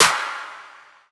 011 Snare Turnt 2.wav